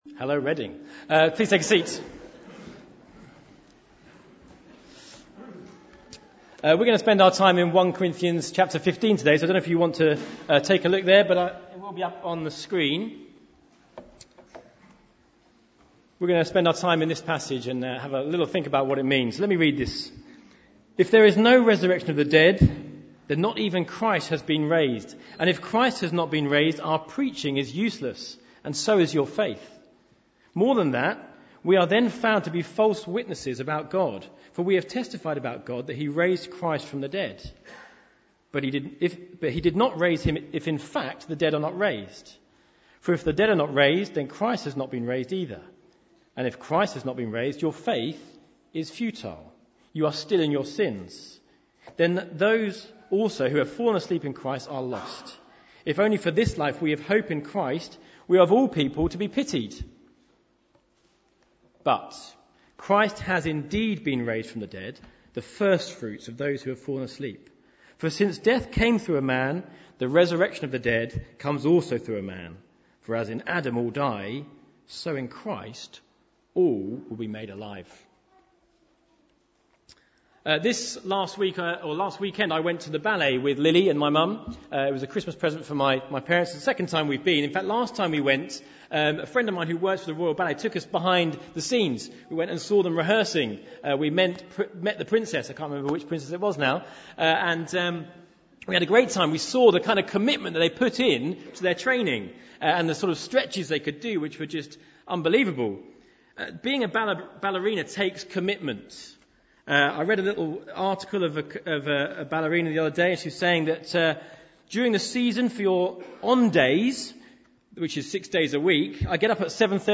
Baptism Service April 2017